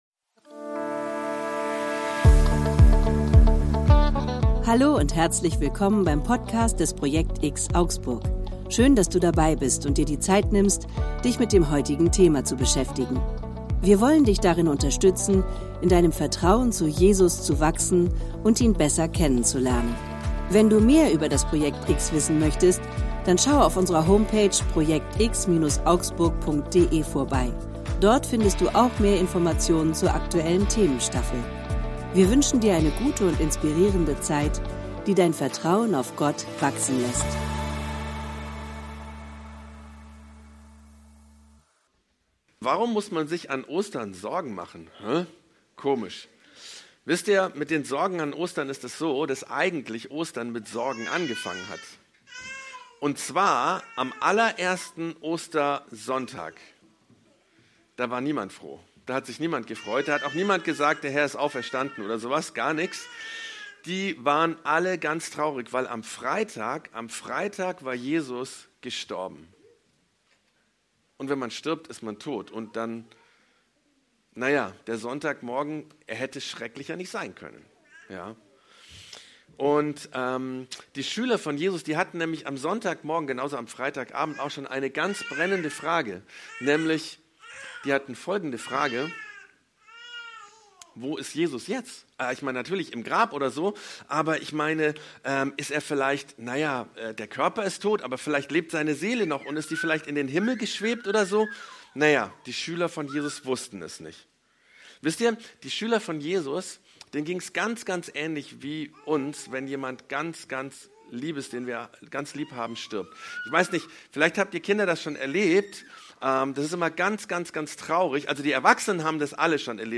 Predigten im Rahmen der church zone vom projekt_X in Augsburg